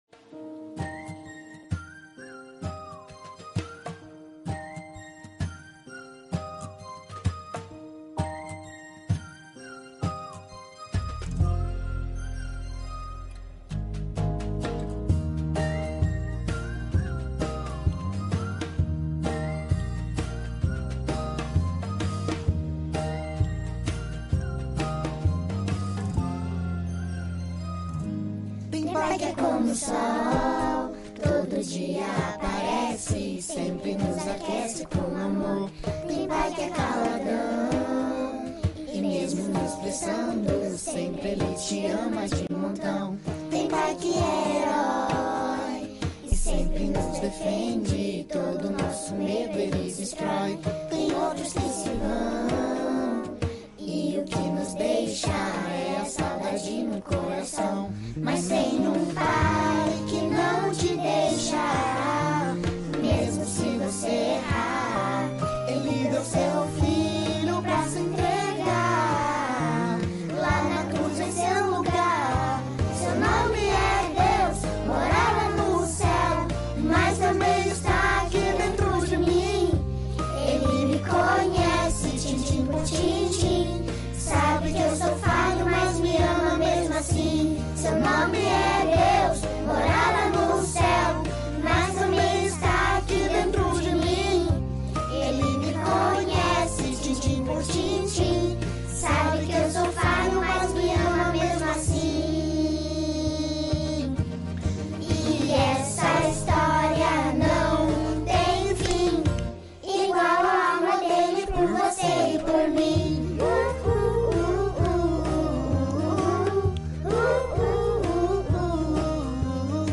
Mensagem
na Igreja Batista do Bacacheri